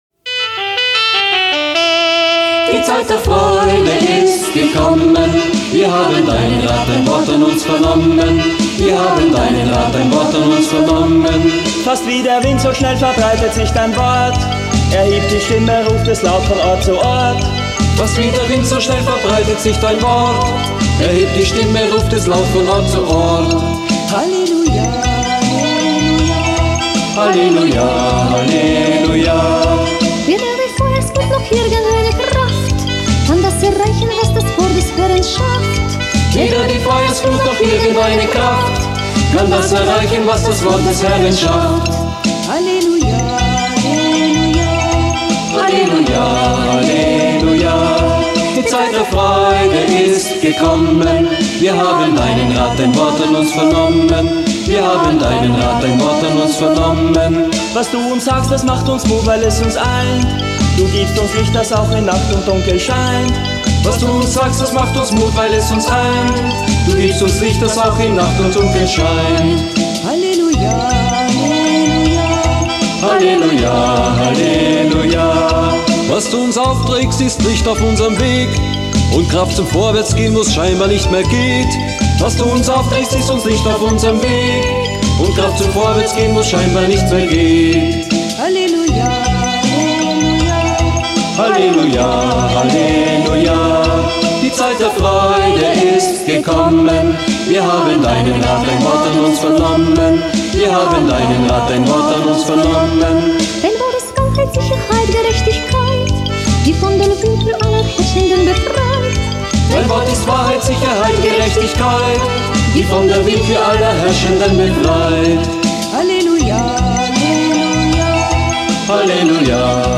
Brasilianische Messe